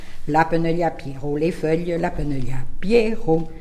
Divertissements d'adultes - Couplets à danser
branle : courante, maraîchine
Pièce musicale inédite